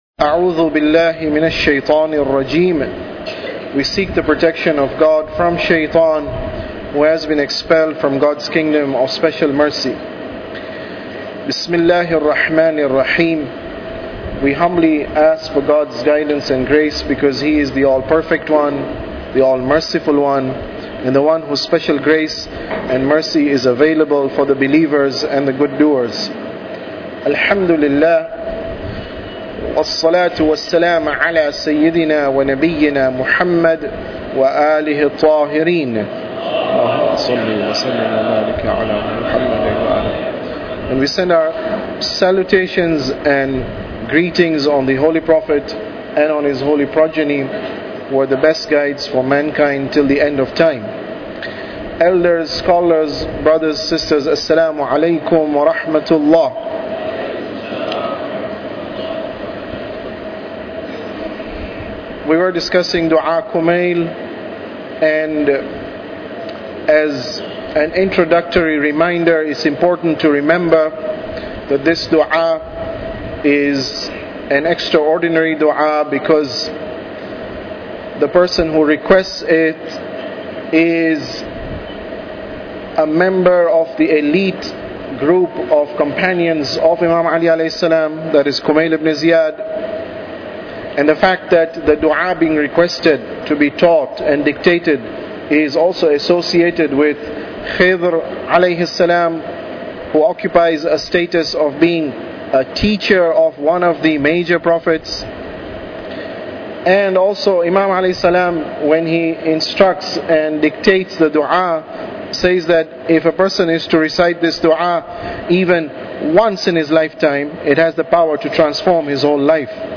Tafsir Dua Kumail Lecture 25